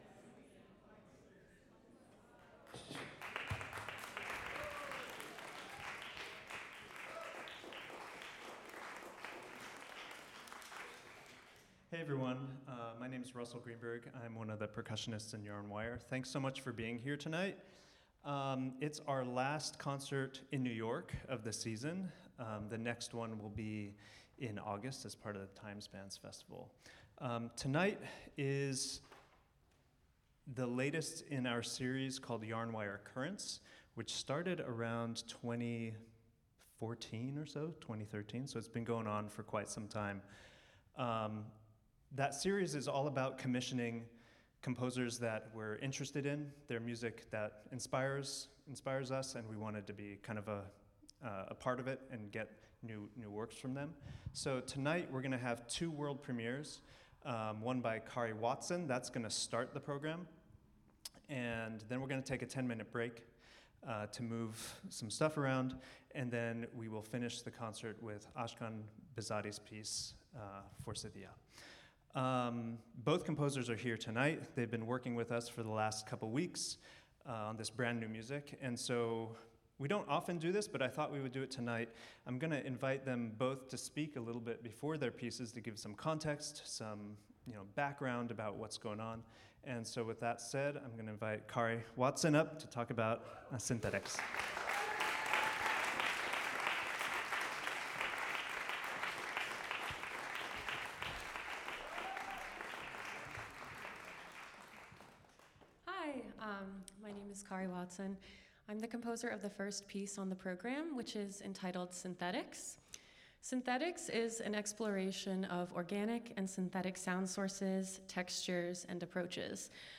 piano
percussion